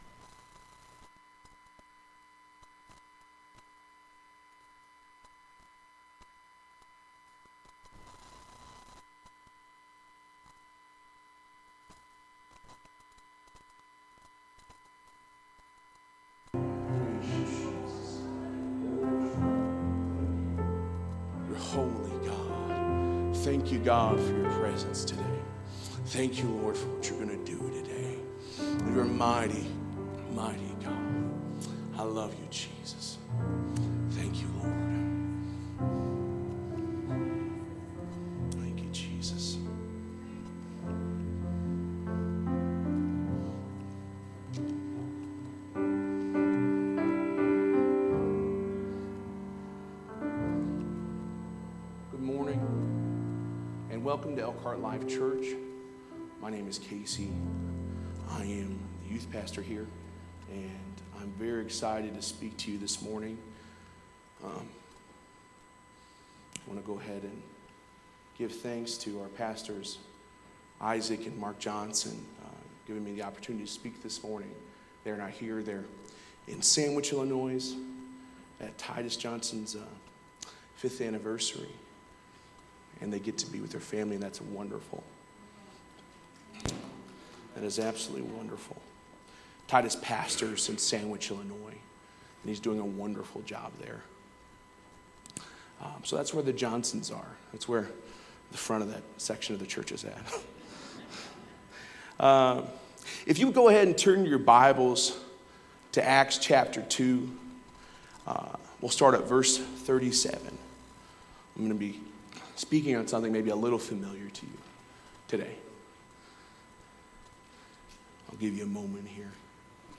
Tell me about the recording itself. Sunday Service A Lesson in Acts